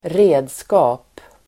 Uttal: [²r'e:dska:p]